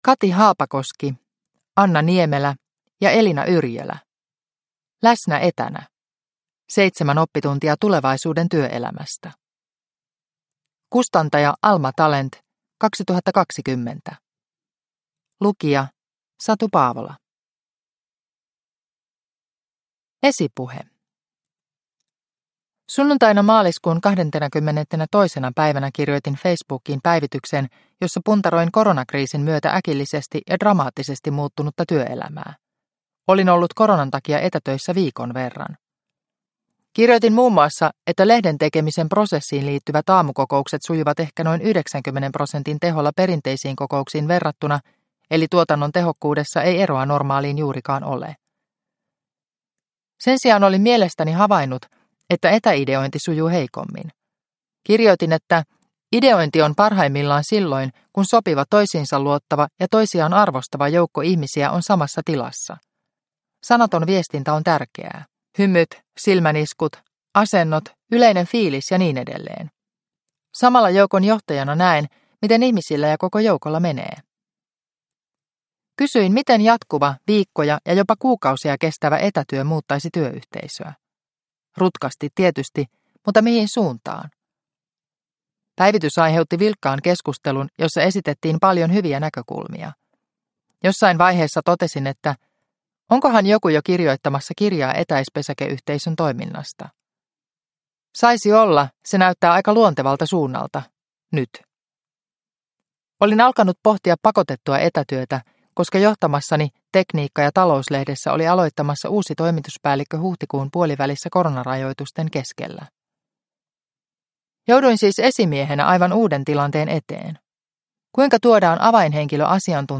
Läsnä etänä – Ljudbok – Laddas ner